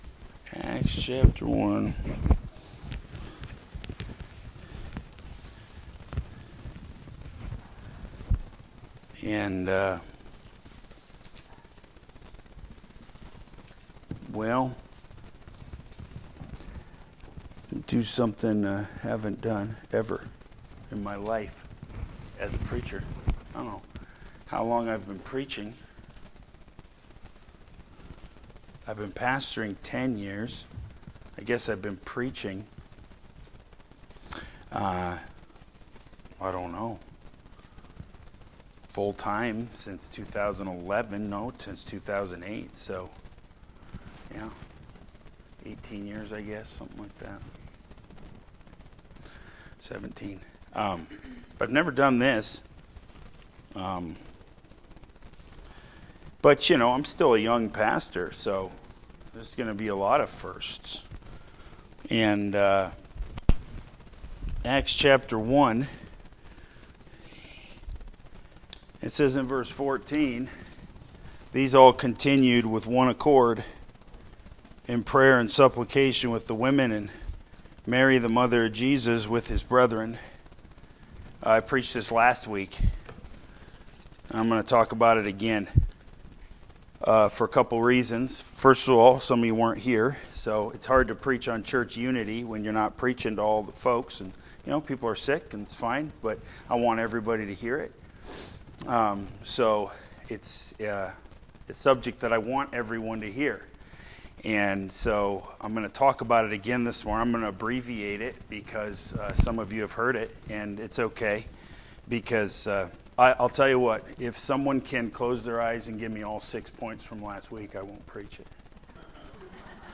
Main Service